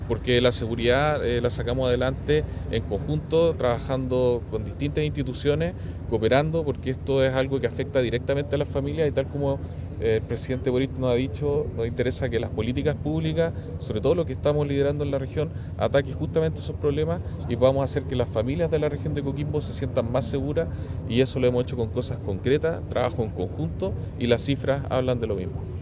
En tanto, el Delegado Regional Presidencial, Galo Luna subrayó que